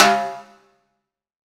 HTIMBALE H1G.wav